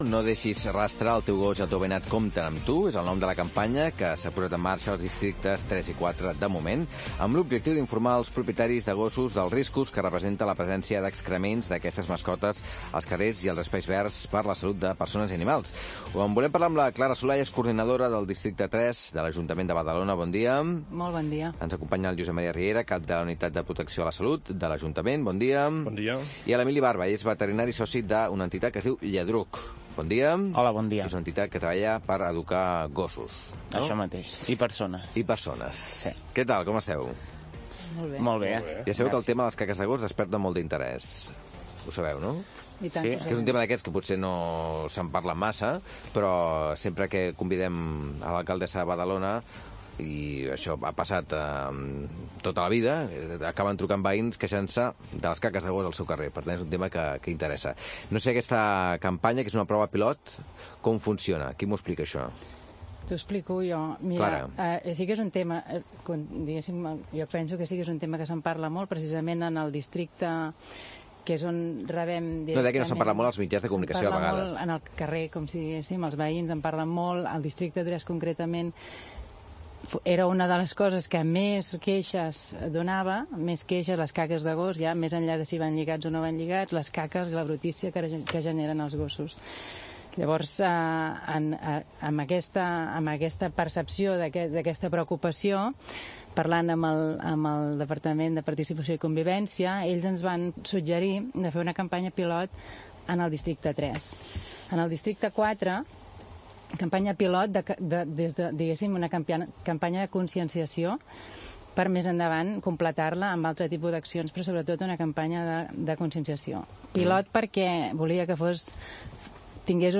Es por ello que varios medios de comunicación han querido entrevistarnos, realizarnos reportajes y nos han incluido en sus telediarios.